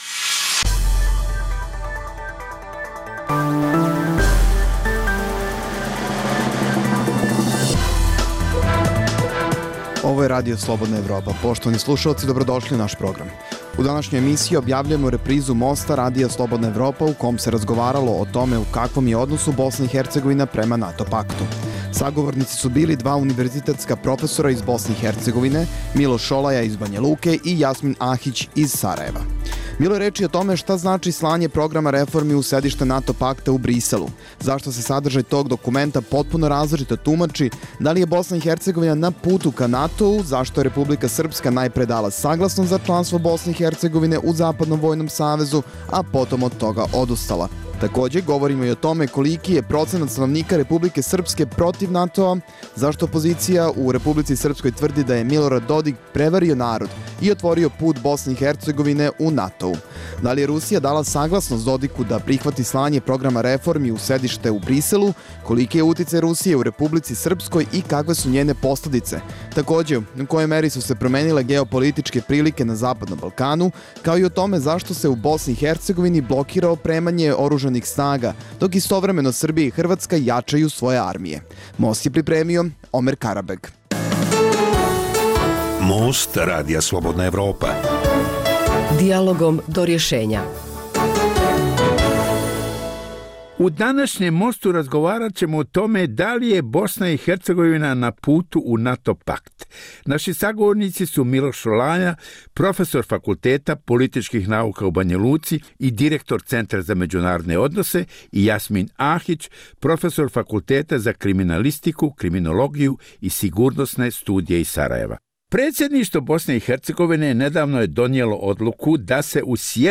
u kojem ugledni sagovornici iz regiona razmatraju aktuelne teme.